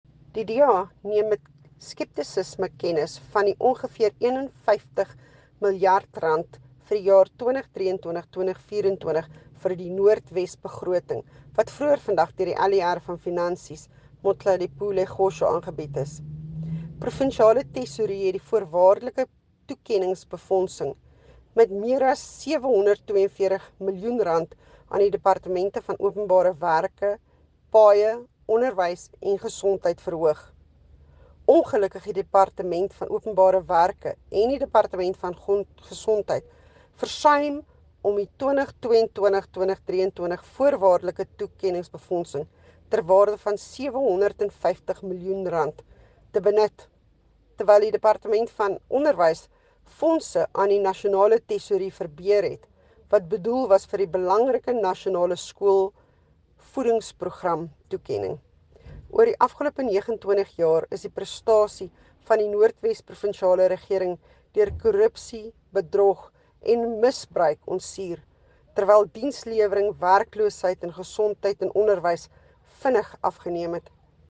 Note to Broadcasters: Find linked soundbites in
Afrikaans by Jacqueline Theologo MPL